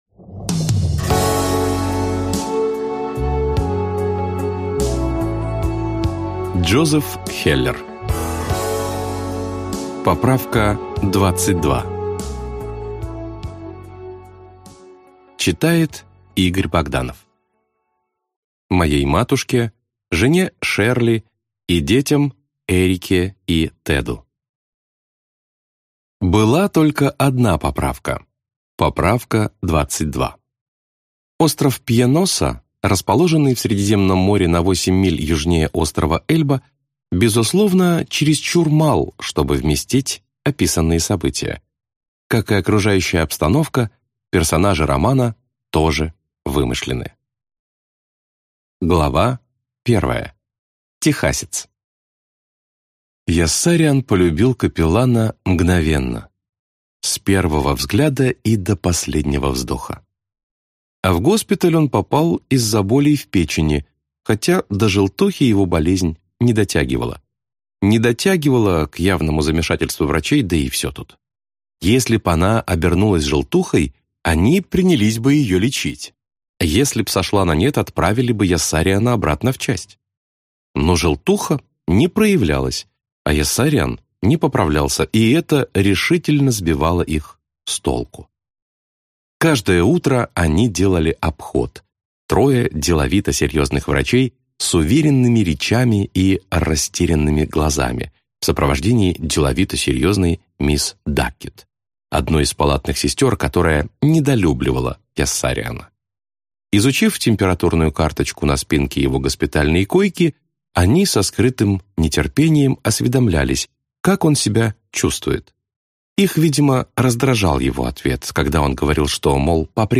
Аудиокнига Поправка-22 - купить, скачать и слушать онлайн | КнигоПоиск
Аудиокнига «Поправка-22» в интернет-магазине КнигоПоиск ✅ Зарубежная литература в аудиоформате ✅ Скачать Поправка-22 в mp3 или слушать онлайн